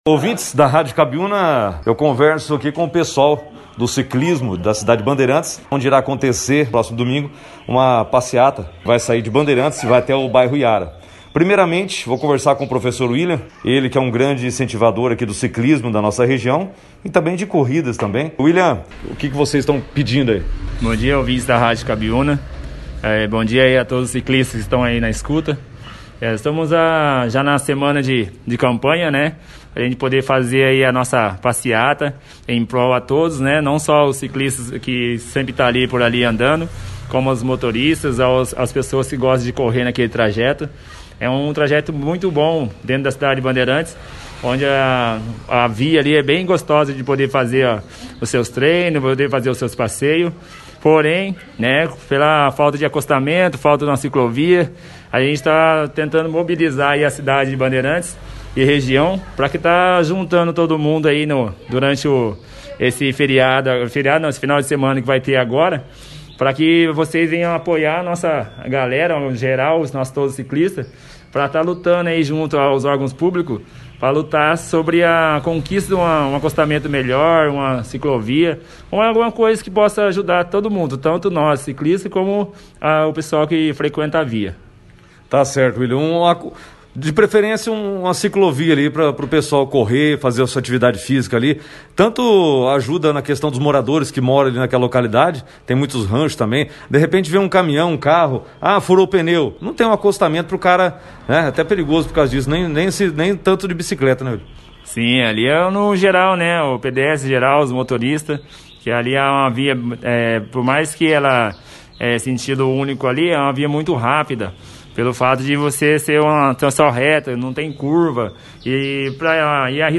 Membros da comissão, participaram da 1ª edição do jornal Operação Cidade desta terça-feira, 09/02, detalhando como será o evento e como os ciclistas podem participarem. https